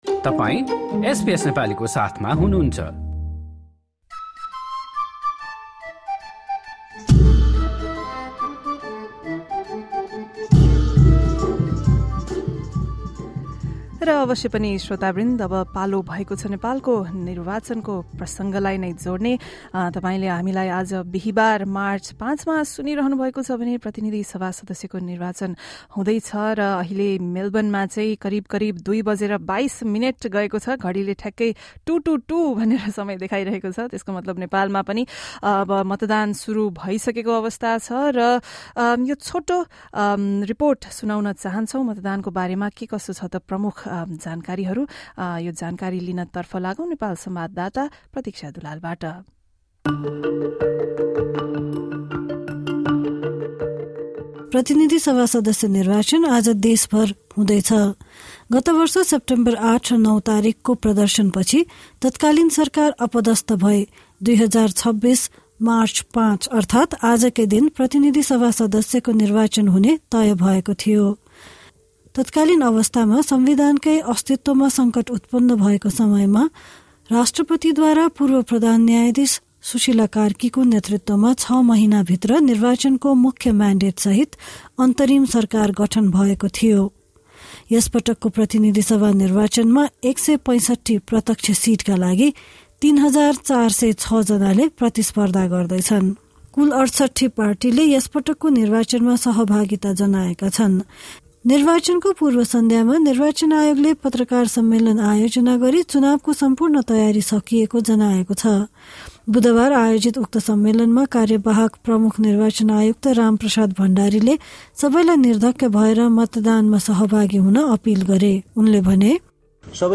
Listen to the Nepal election segment from our radio program on Thursday, 5 March, featuring key details about the polls, the candidates and the lead-up to the election.